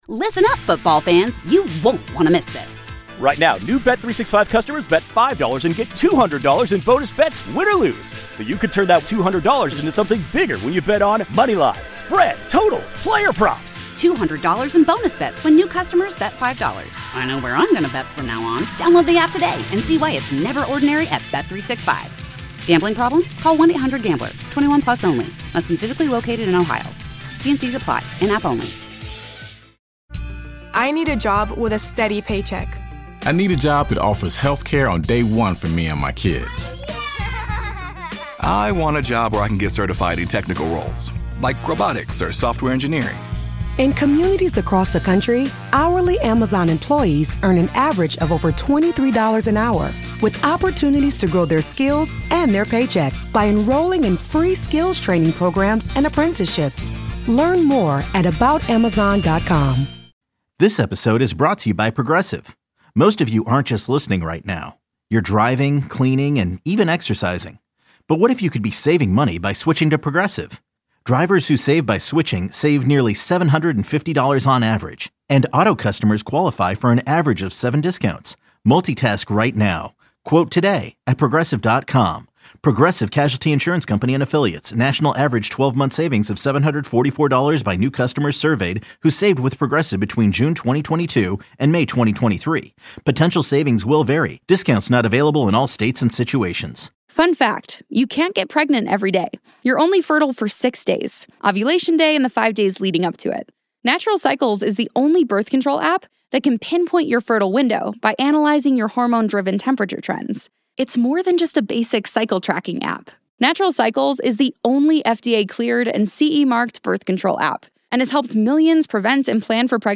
Indulge your inner curiosity with caller driven conversation that makes you feel like you’re part of the conversation—or even better, eavesdropping on someone else’s drama.